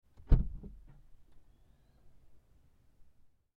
Звуки багажника
Звук открытия багажника записанный внутри автомобиля